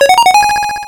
RedCoin2.wav